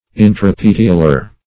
Search Result for " intrapetiolar" : The Collaborative International Dictionary of English v.0.48: Intrapetiolar \In`tra*pet"i*o*lar\, a. (Bot.)